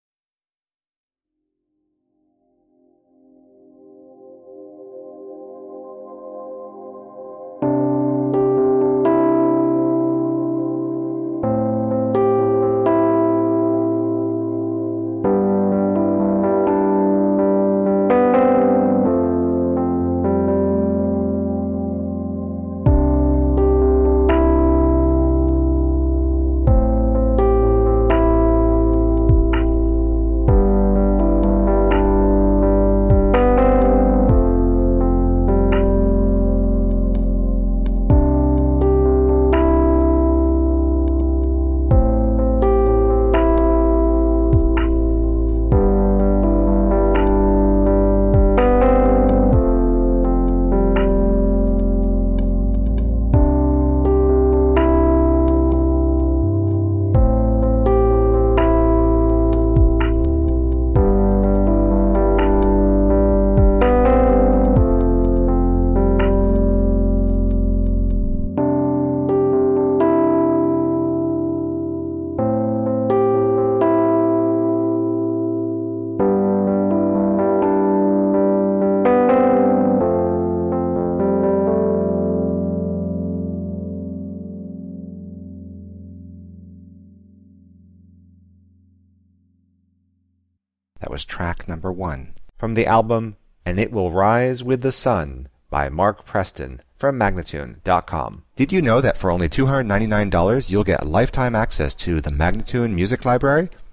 Uplifting electronica and melodic soundscapes.
Recorded on the island of Martha's Vineyard, MA USA